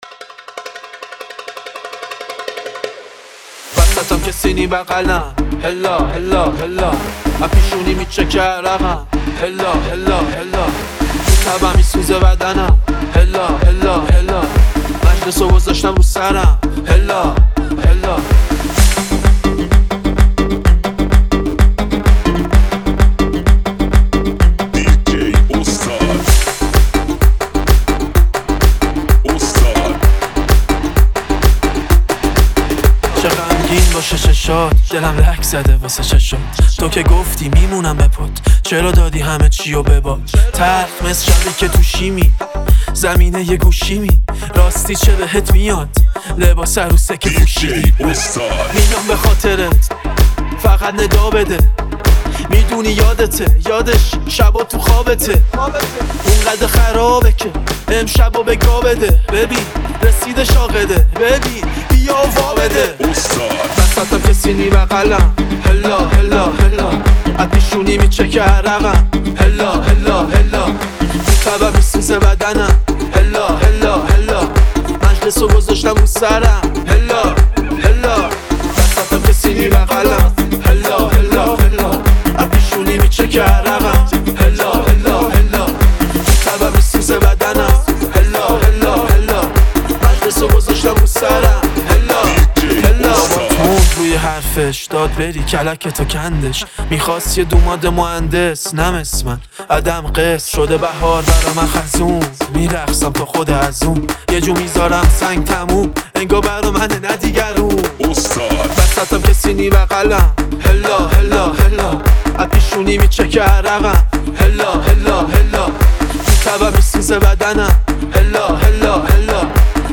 ریمیکس رپی
ریمیکس جدید رپ